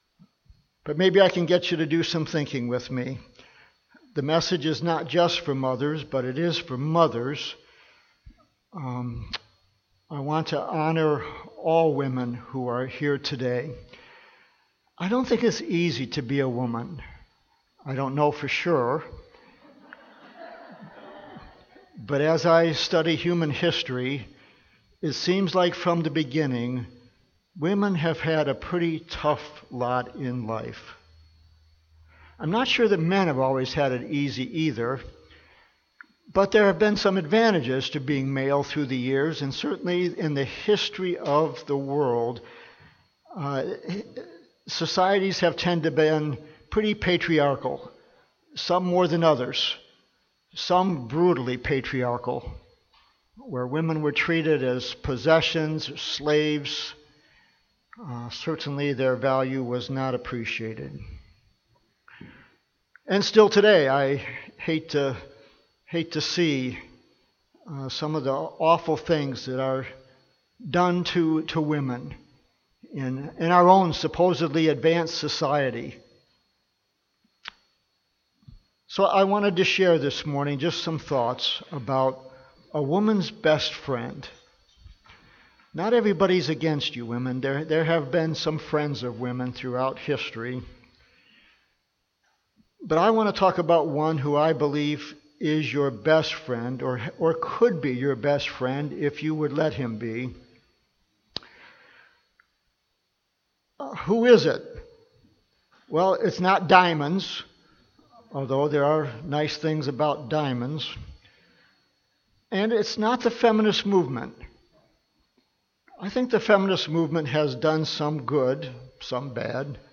Home › Sermons › Mother’s Day: A Woman’s Best Friend